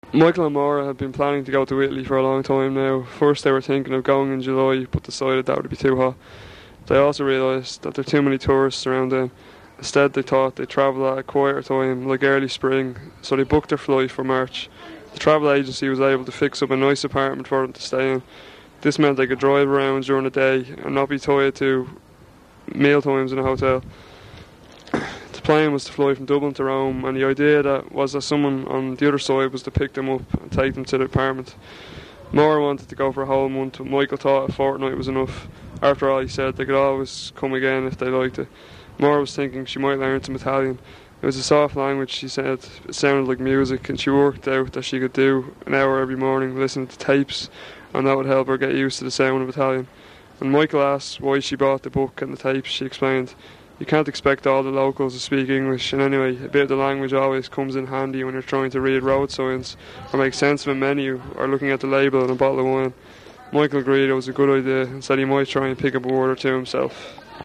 Dublin English, broad
This vintage has meant that the popular variety of English in the city is quite far removed from other varieties on the British mainland. Noticeable in this sound file are (1) [ʊ] in the STRUT lexical class, (2) glottalisation of /t/ or shift to [h], (3) an open diphthong in GOAT, i.e. [gʌʊʔ], (4) a fronted onset in MOUTH, i.e. [mɛʊt]. Note also the lengthening and breaking of long vowels, e.g. mean [mijən] and very open realisation of back vowels, e.g. lot [lah]. Dublin English is furthermore only weakly rhotic.
Ireland_Dublin_Broad.wav